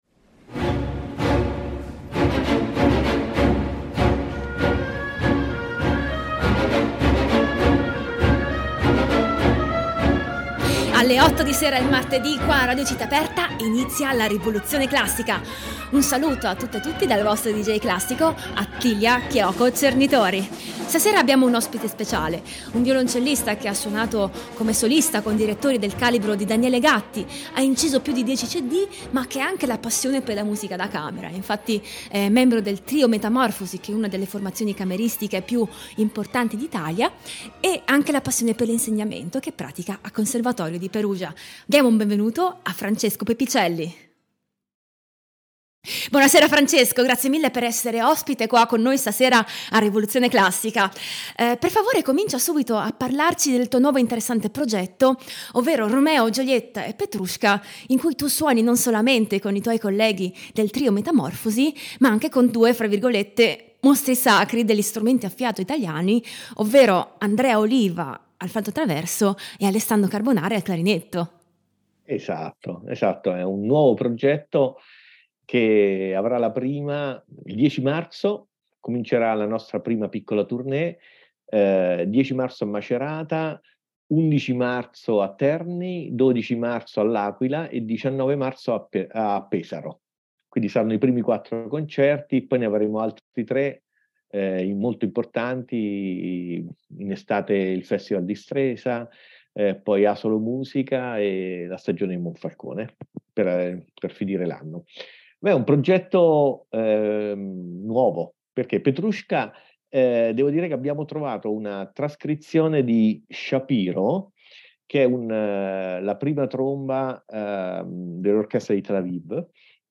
Ospite di questa puntata il violoncellista